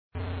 move_player.ogg